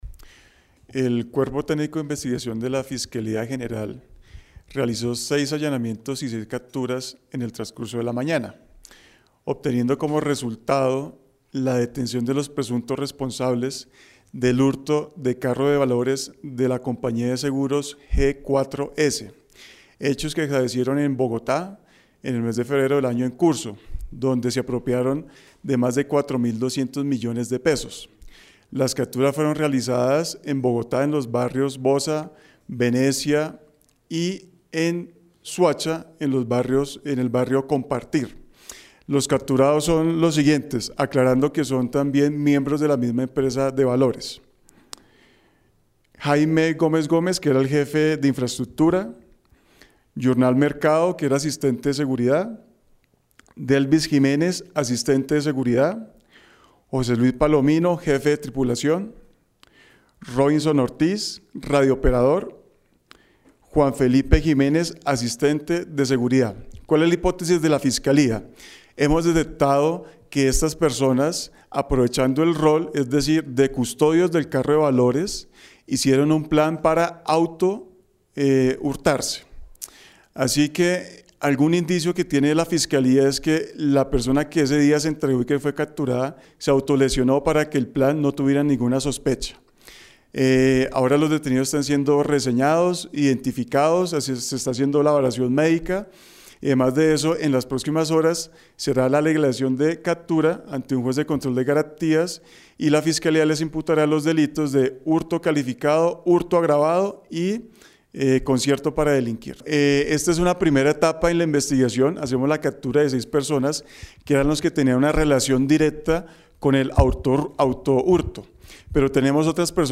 Lugar: Nivel Central Fiscalía General de la Nación, Bogotá.